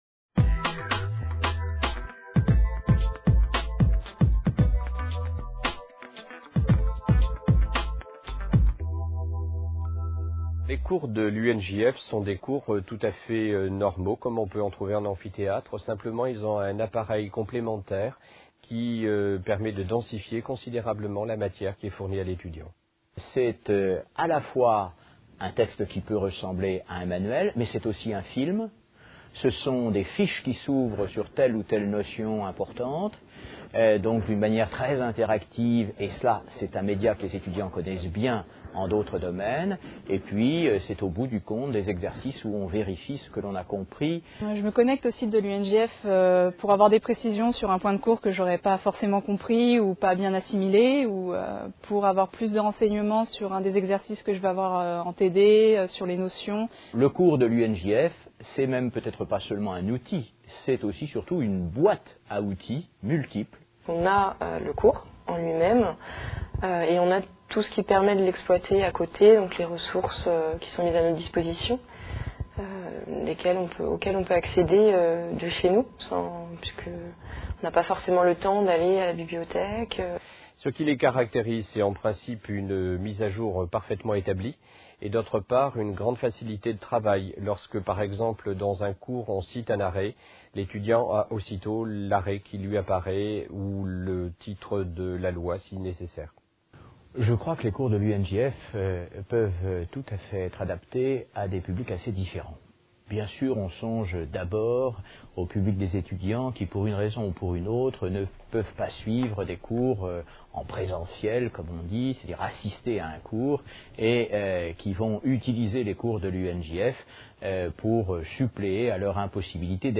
Témoignages d'enseignants et des étudiants utilisateurs des cours de l'UNJF | Canal U